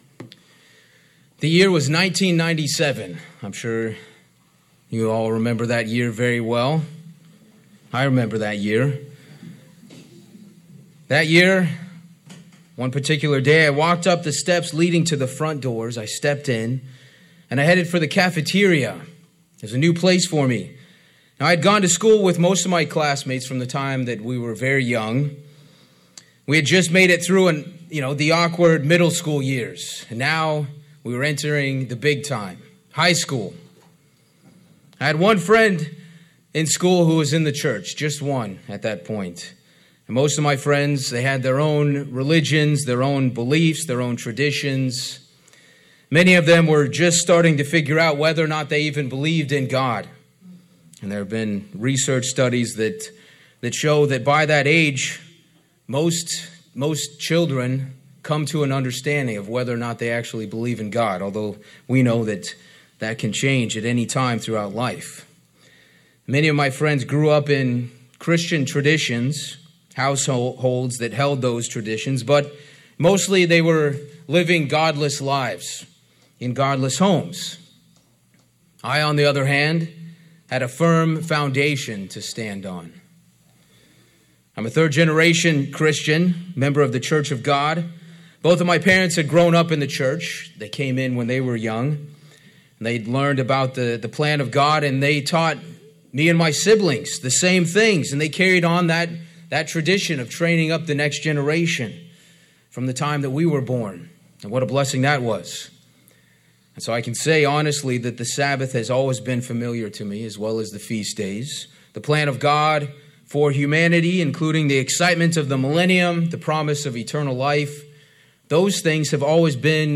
Sermons
Given in New York City, NY New Jersey - North